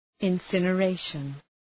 Προφορά
{ın,sınə’reıʃən}